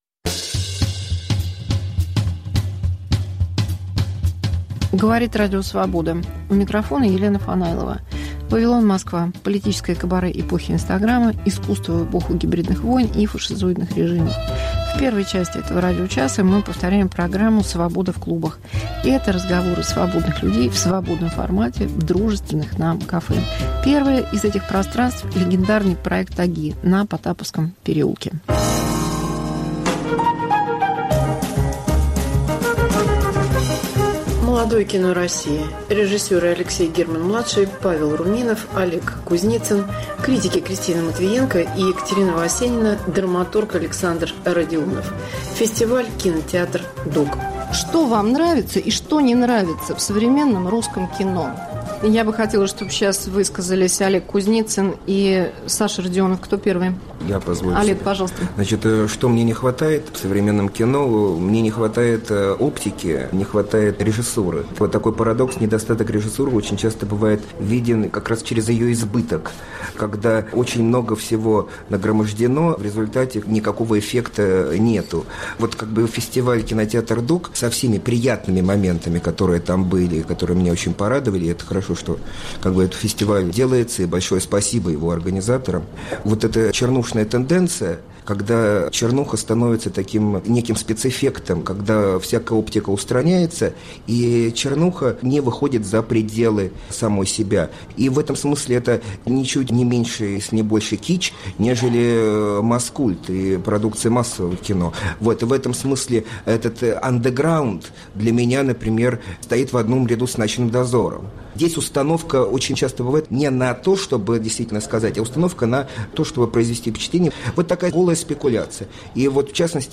Мегаполис Москва как Радио Вавилон: современный звук, неожиданные сюжеты, разные голоса. 1. Молодое кино России. 2.